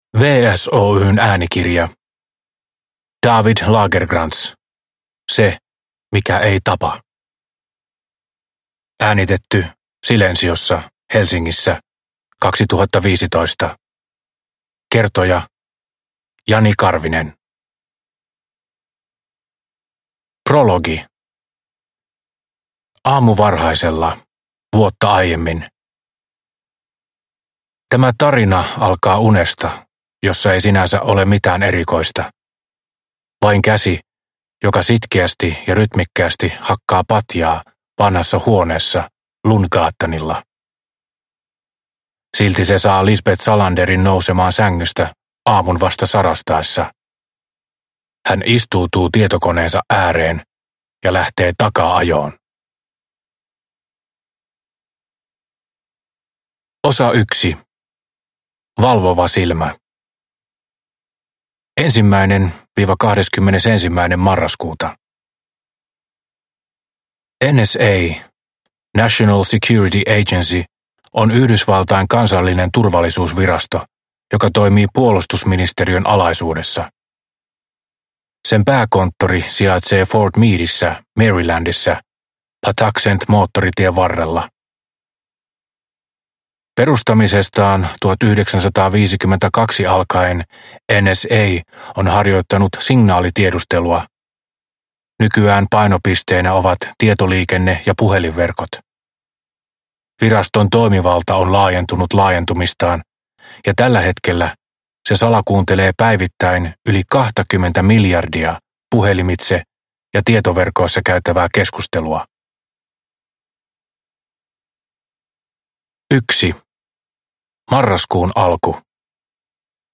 Se mikä ei tapa – Ljudbok – Laddas ner